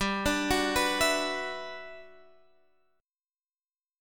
Listen to GM13 strummed